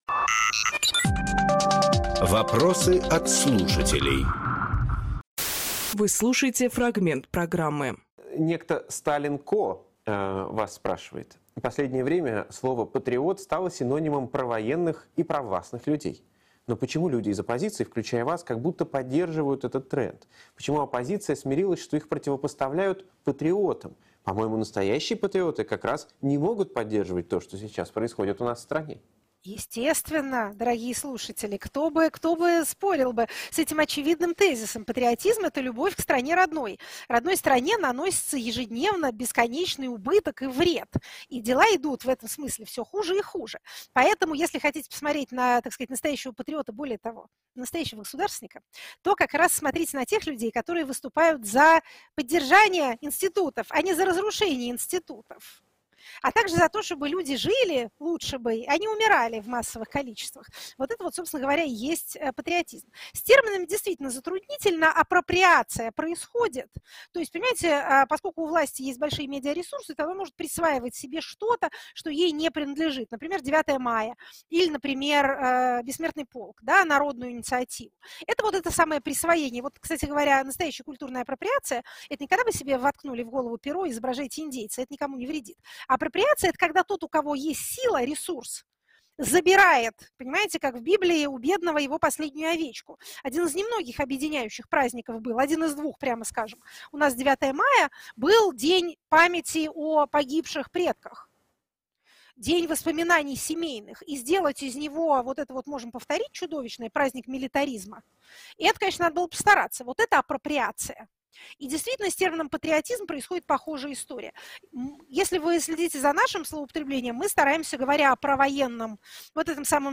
Екатерина Шульманполитолог
Фрагмент эфира от 26.12.23